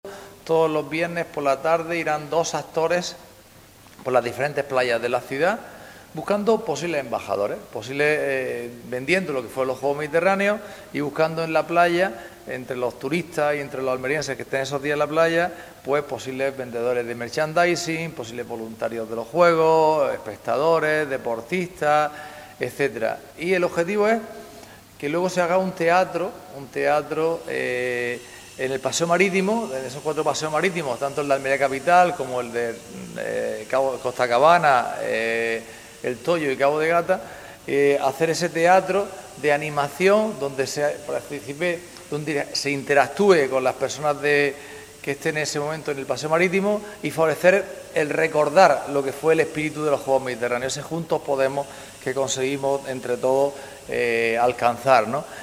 en rueda de prensa
ANTONIO-JESUS-CASIMIRO-CONCEJAL-CIUDAD-ACTIVA-EMBAJADORES-PLAYEROS.mp3